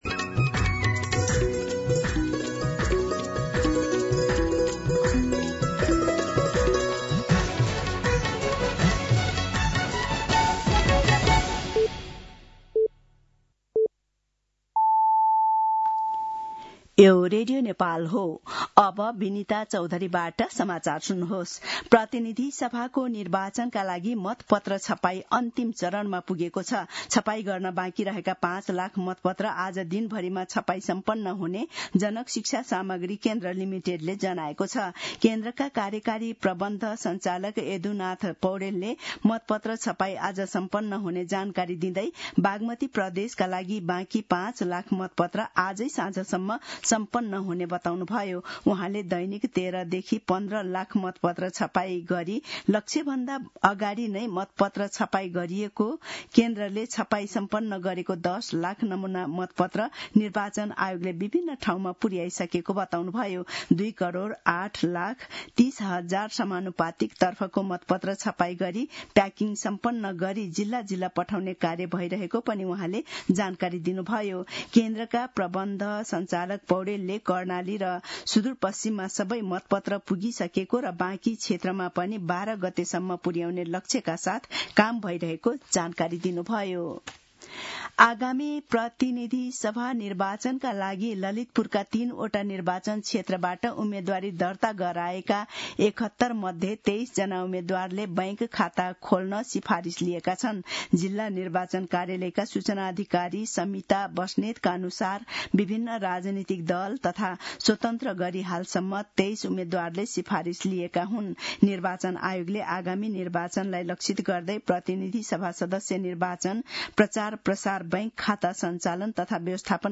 मध्यान्ह १२ बजेको नेपाली समाचार : ४ फागुन , २०८२
12-pm-News-11-4.mp3